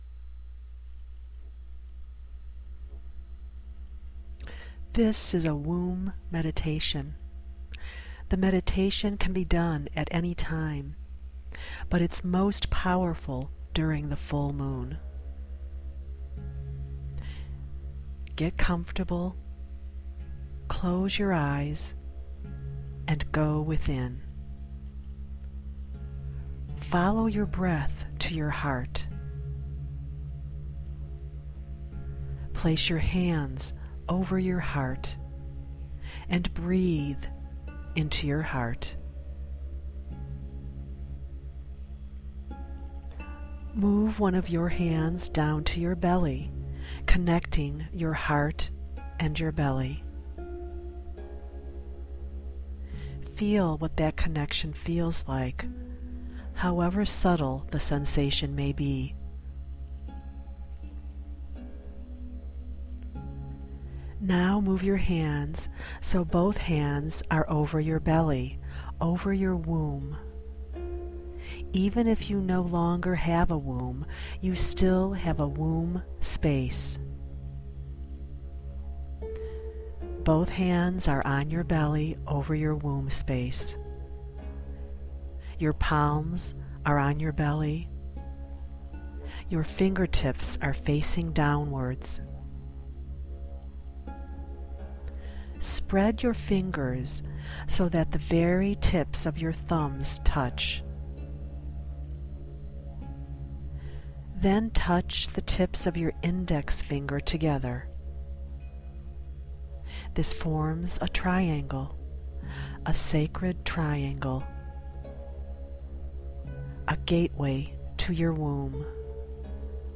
Here is your free download of the Womb Meditation.
full-womb-meditation-with-music13-minutes.mp3